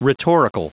Prononciation du mot rhetorical en anglais (fichier audio)
Prononciation du mot : rhetorical